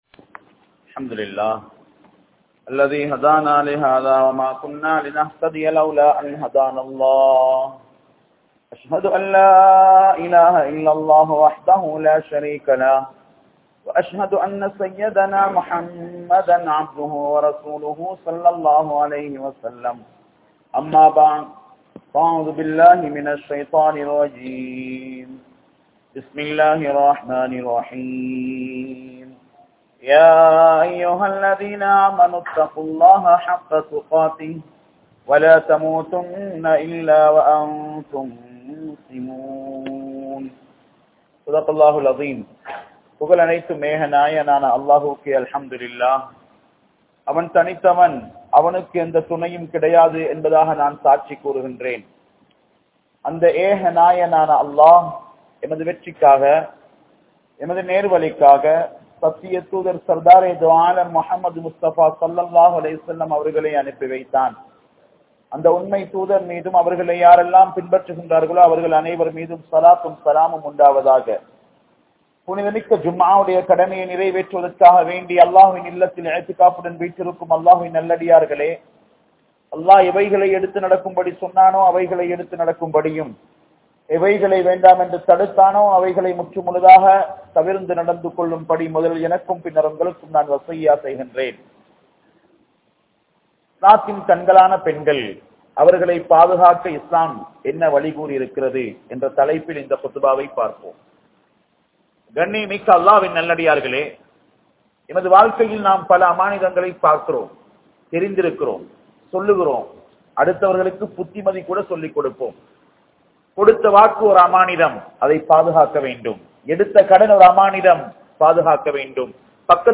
Pengalin Paathuhaavalar Yaar? (பெண்களின் பாதுகாவலர் யார்?) | Audio Bayans | All Ceylon Muslim Youth Community | Addalaichenai